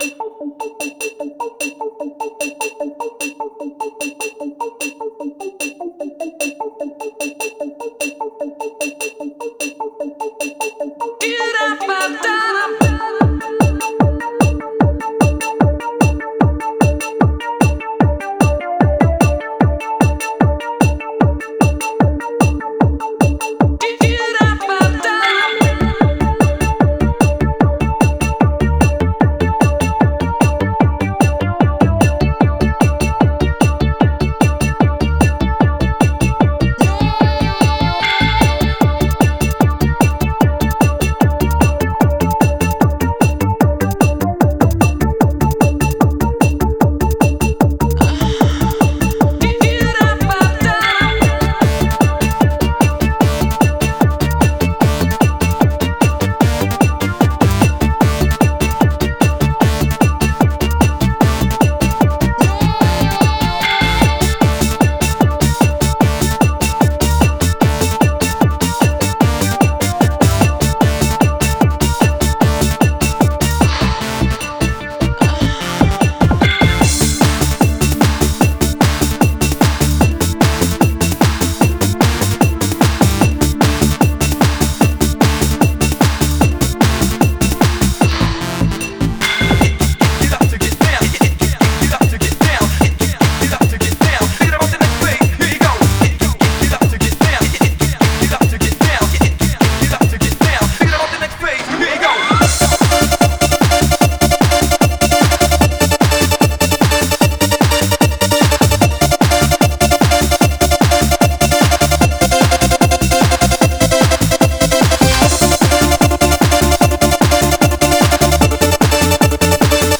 Genre: Makina.